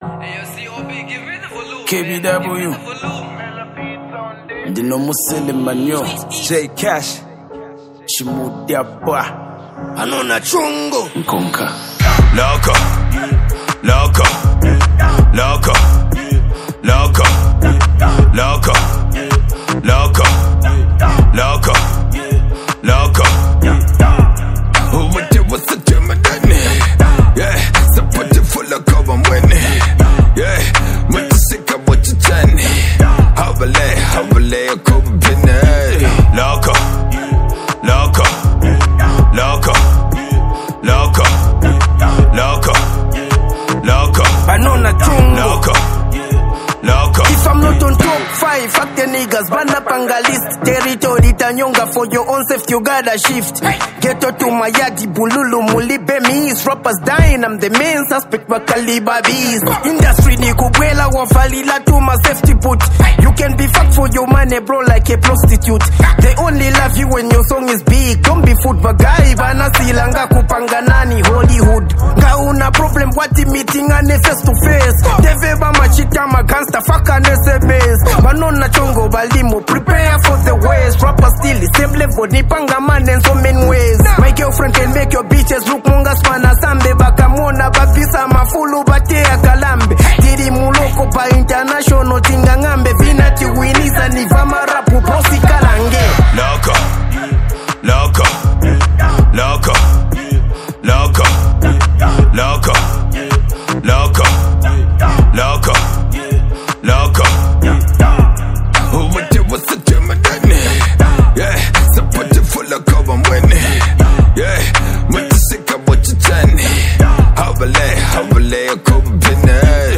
The energy here is unmatched.